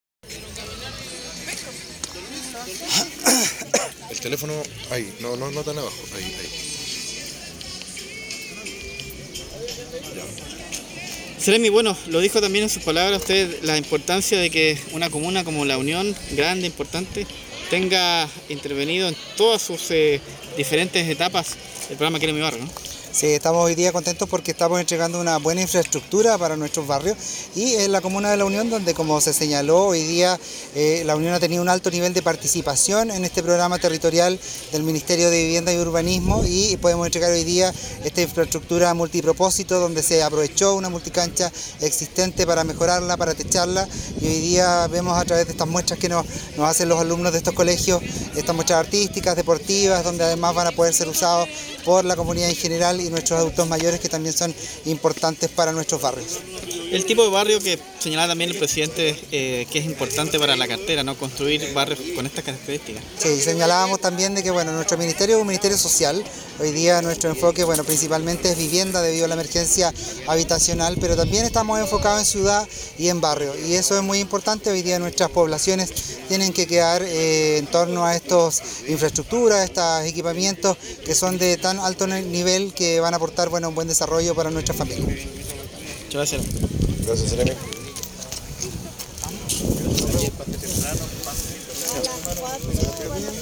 seremi-Daniel-Barrientos-inauguracion-polideportivo.mp3